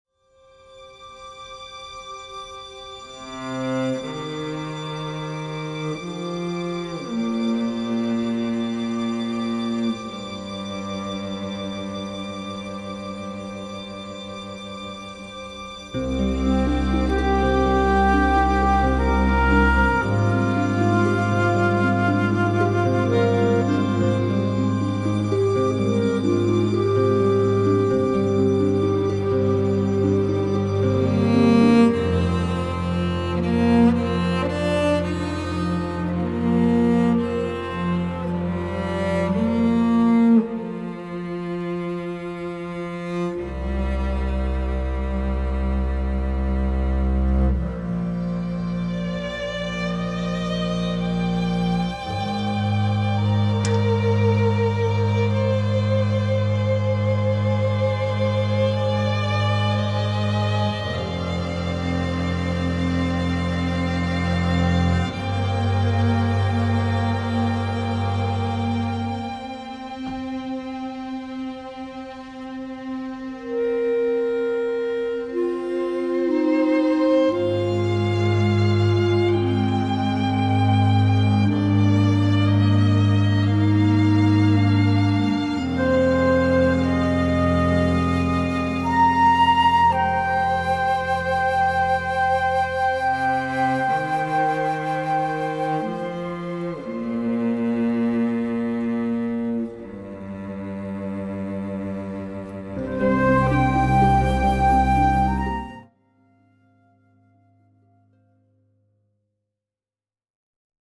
Leap Into Eternity (Live Orchestra)
This is a track with a very somber and tranquil mood.